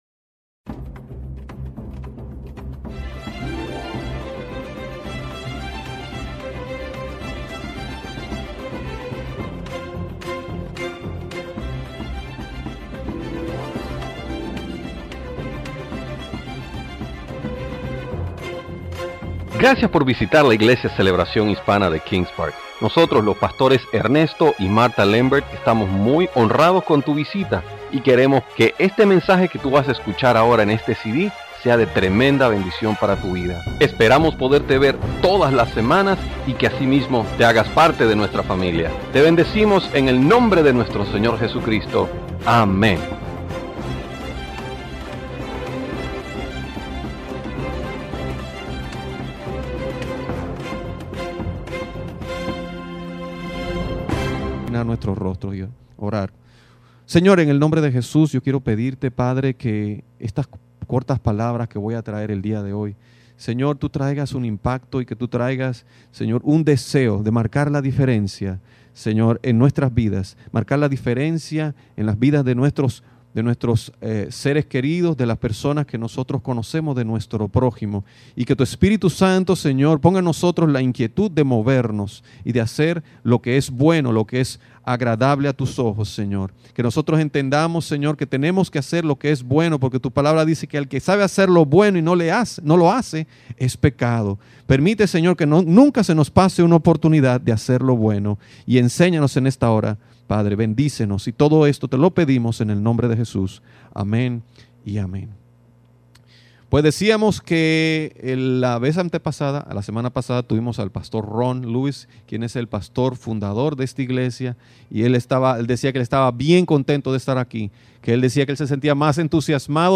Series: Servicio Dominical